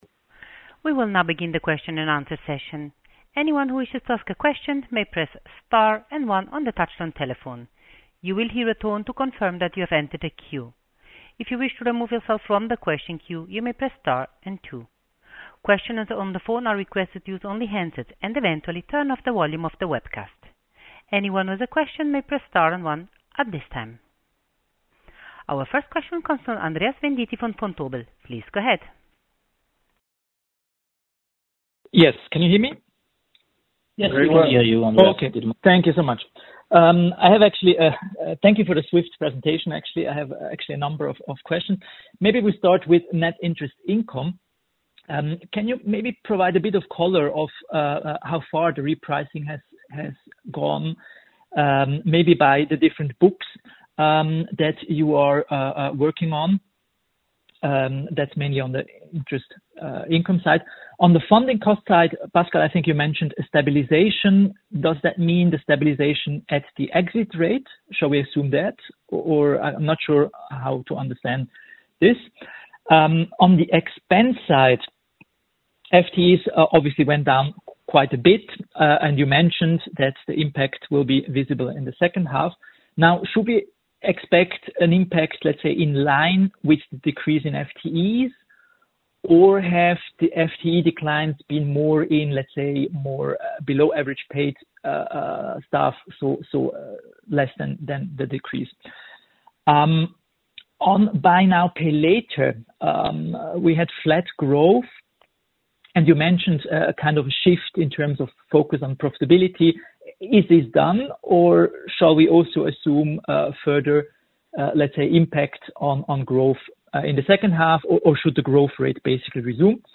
webcast-replay-qa-halbjahresergebnis-2024.mp3